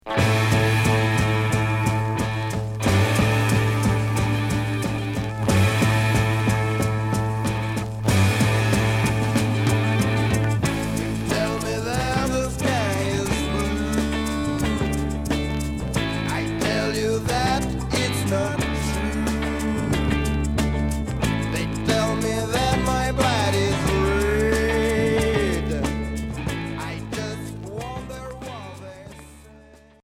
Heavy rock Deuxième 45t retour à l'accueil